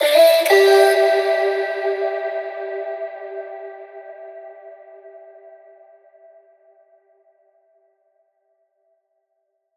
VR_vox_hit_echo_Emin.wav